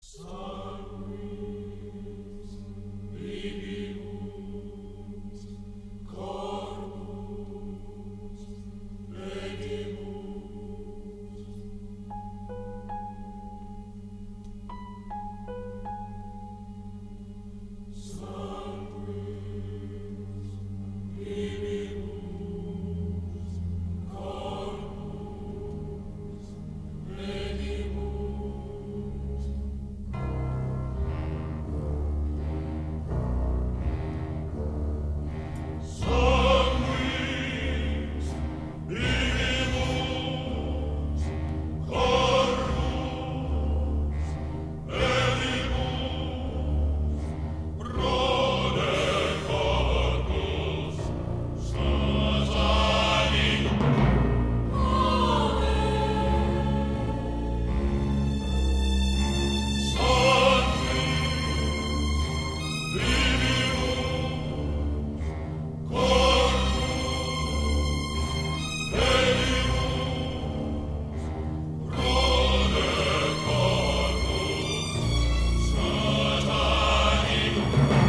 sobrecogedora partitura musical
inspirada en el canto gregoriano